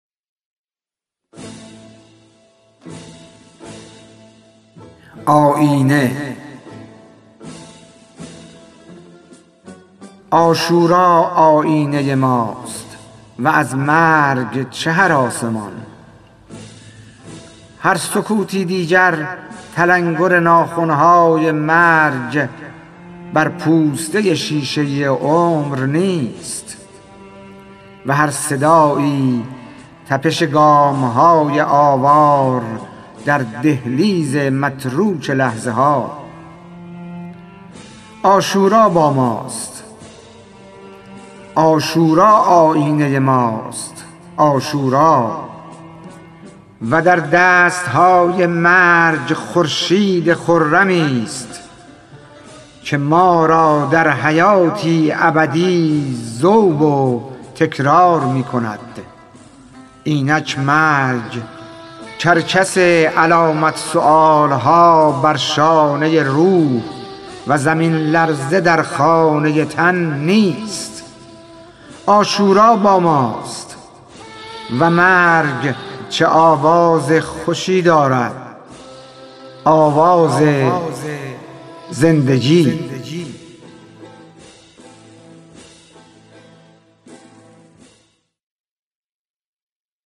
خوانش شعر سپید عاشورایی/ ۱